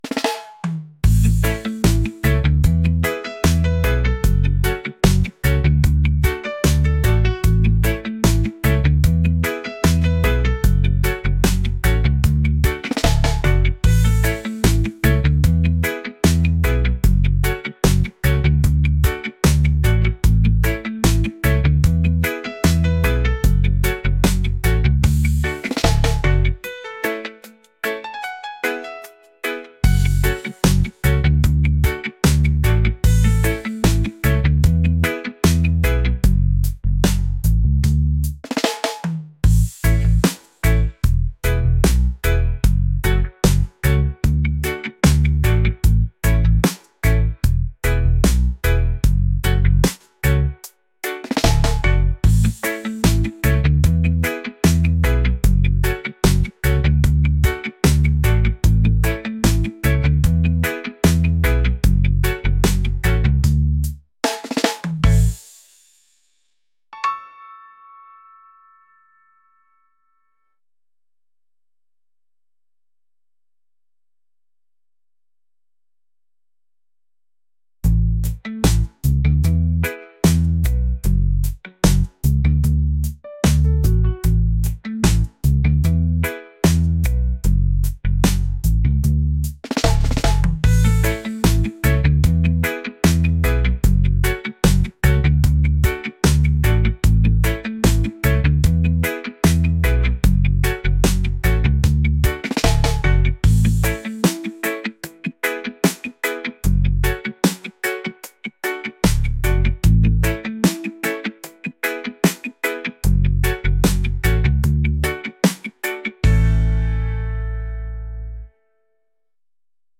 laid-back | positive | vibes | reggae